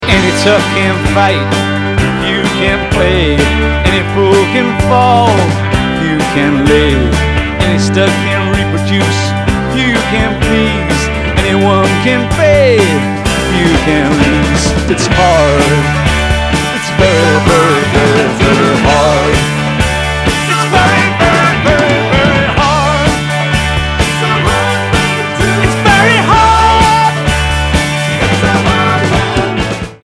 Sound Samples (All Tracks In Stereo Except Where Noted)
Mono